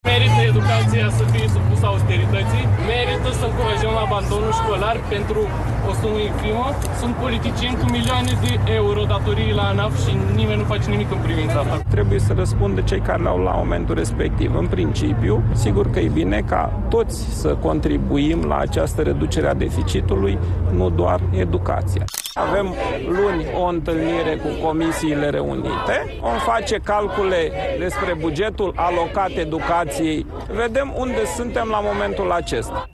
Cum Bugetul de stat și Bugetul asigurărilor sociale încep să fie discutate de luni în Comisiile din Parlament, și situația Educației va fi analizată. Se vor face calcule, a explicat ministrul Mihai Dimian: